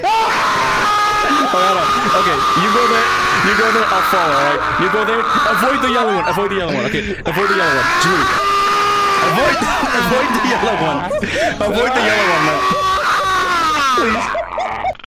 Habibi Jumpscare Efeito Sonoro: Soundboard Botão
Habibi Jumpscare Botão de Som